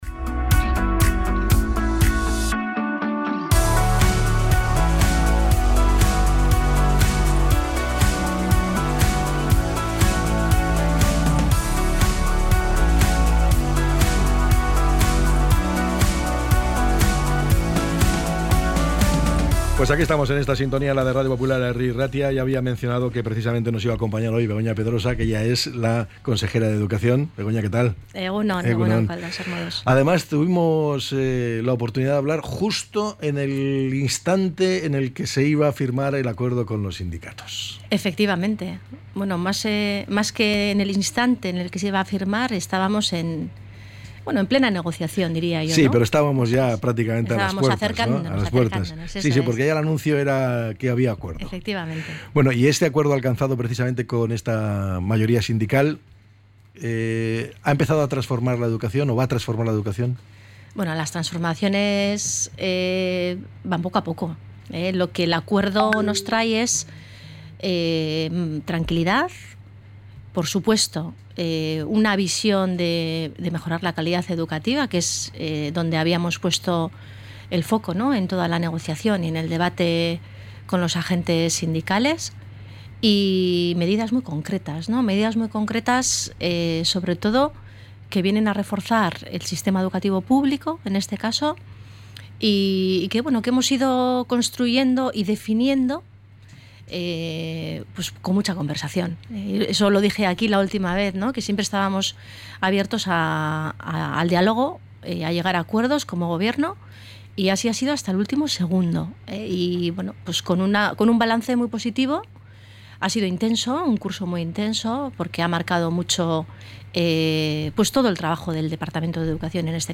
ENTREVISTA-BEGONA-PEDROSA.mp3